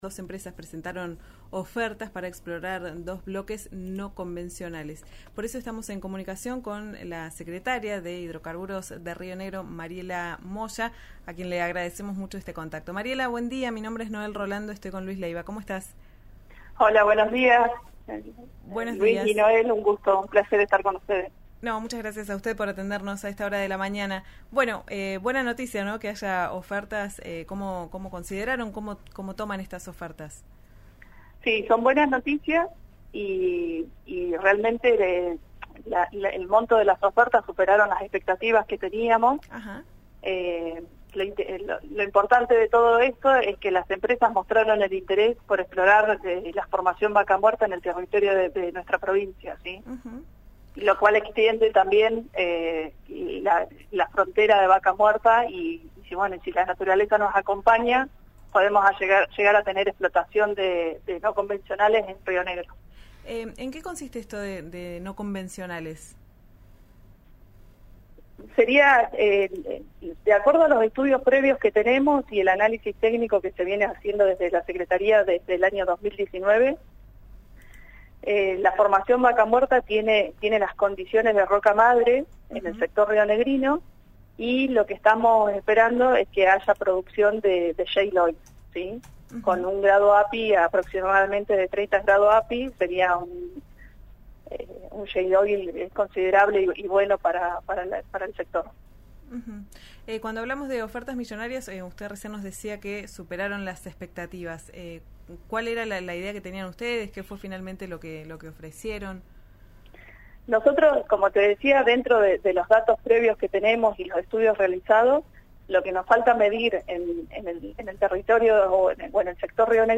Escuchá a la secretaria de hidrocarburos de Rio Negro, Mariela Moya, en RÍO NEGRO RADIO: